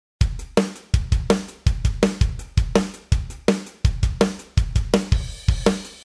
Dビート
もともとハードコアパンクのリズムですが、ロック・メタルなどでも多用されます。
2小節が1セットで、ドッタッ　ドドタッ　ドドタド　ッドタッのパターンです。